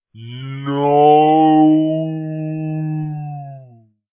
标签： film ambience processed music movies dark cinematic atmosphere theatrical scoring
声道立体声